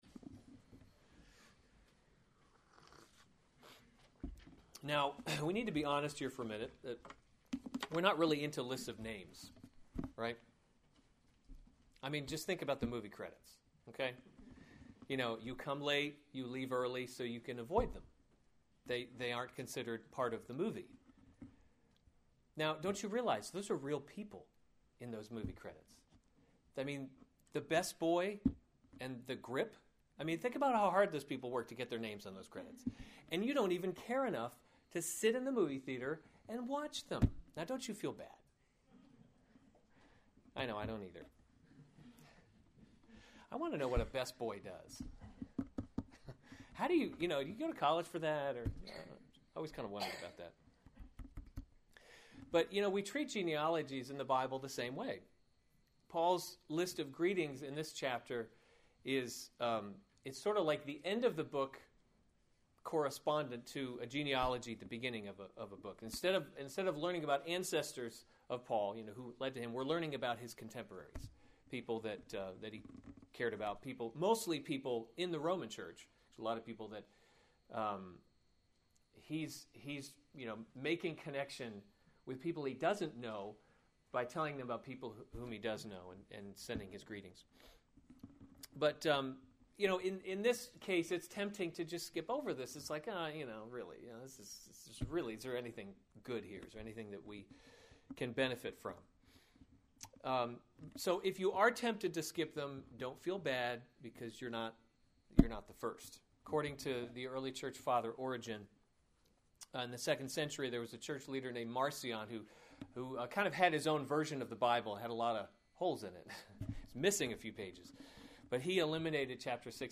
May 23, 2015 Romans – God’s Glory in Salvation series Weekly Sunday Service Save/Download this sermon Romans 16:1-16 Other sermons from Romans Personal Greetings 16:1 I commend to you our sister […]